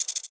rifle_shake2.wav